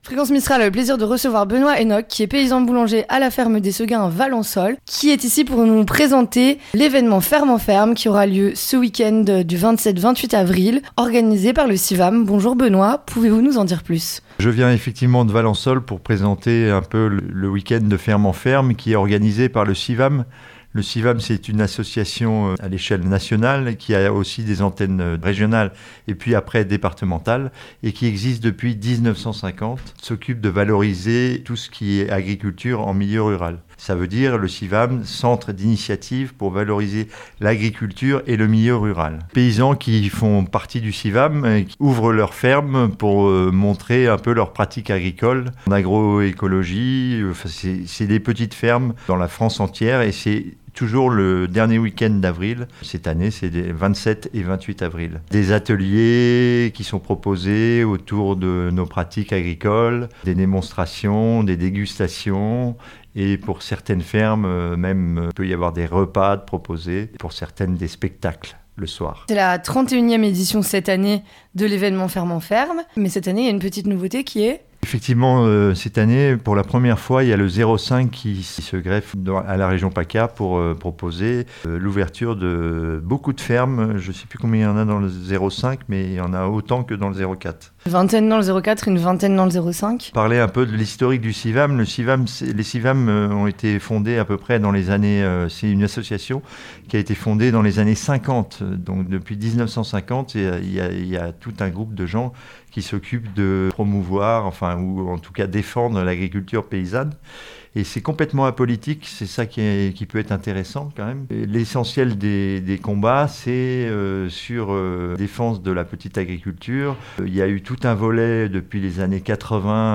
Respect des saisons, réduction des déchets, aspect social, prendre soin, le lien à la culture... Voici quelques thématiques abordées lors de cet entretien.